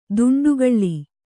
♪ duṇḍugaḷḷi